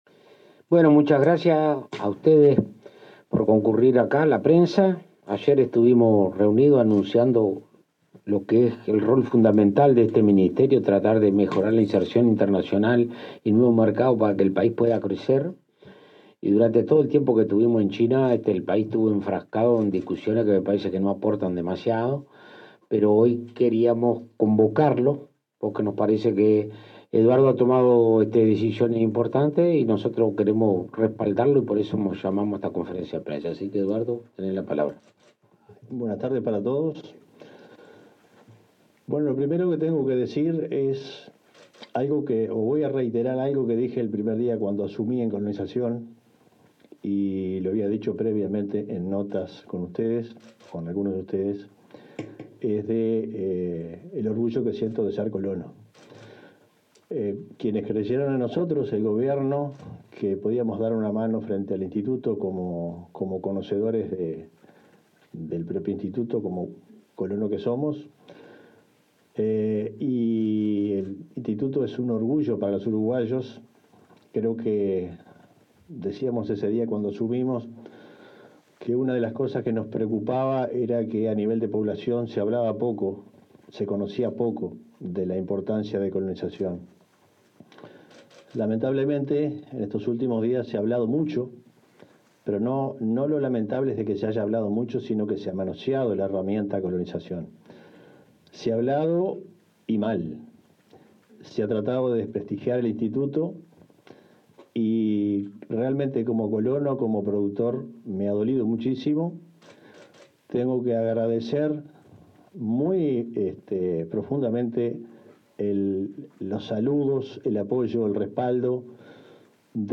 Declaraciones del ministro de Ganadería, Alfredo Fratti, y el titular del INC, Eduardo Viera
Declaraciones del ministro de Ganadería, Alfredo Fratti, y el titular del INC, Eduardo Viera 29/05/2025 Compartir Facebook X Copiar enlace WhatsApp LinkedIn El ministro de Ganadería, Agricultura y Pesca, Alfredo Fratti, y el presidente del Instituto Nacional de Colonización (INC), Eduardo Viera, brindaron una conferencia de prensa en la sede de la cartera.